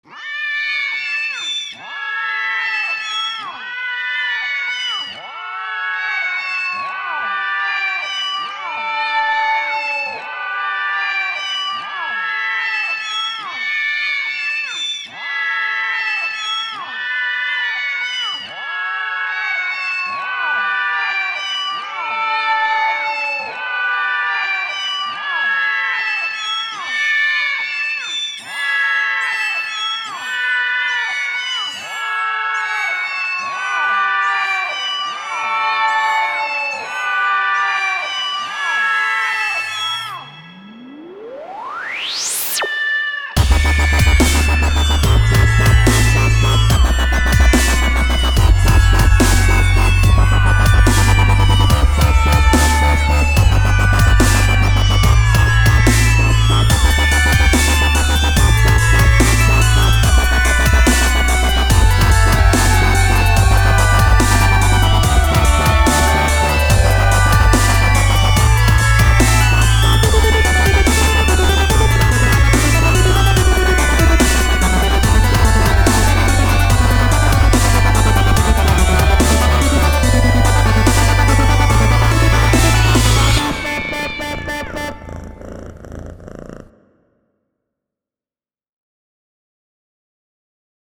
cat_canon.mp3